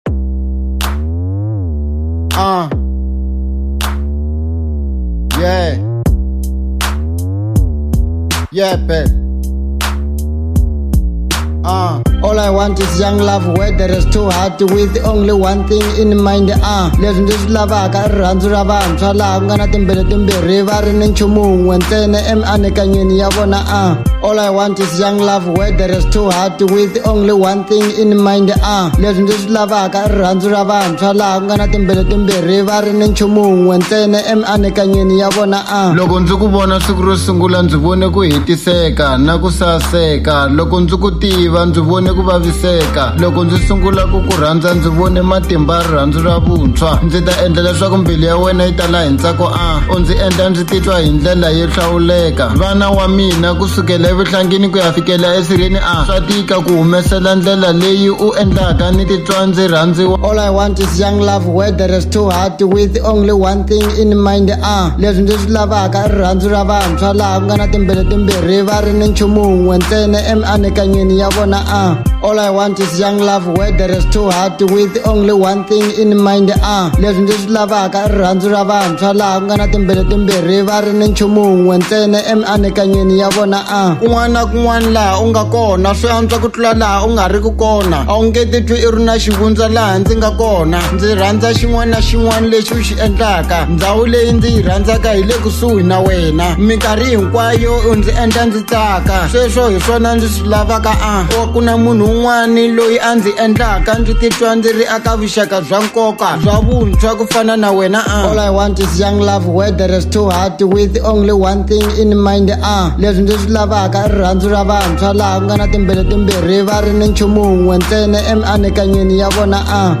03:24 Genre : Hip Hop Size